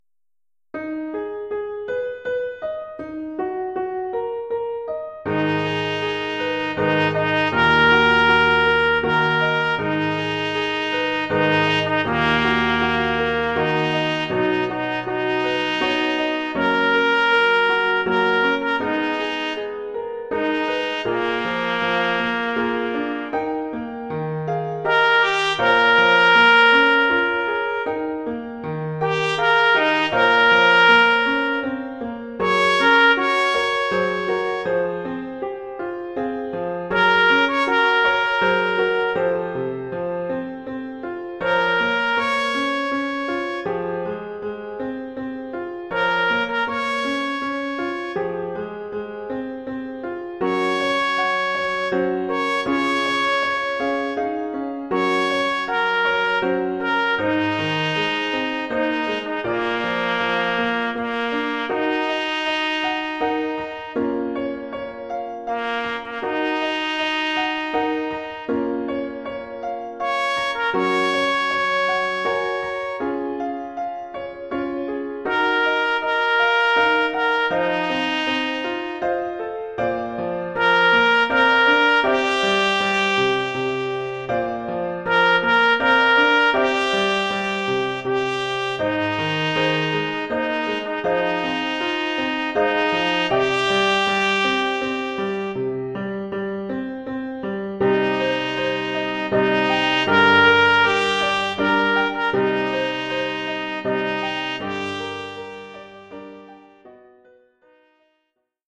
trompette basse mib et piano.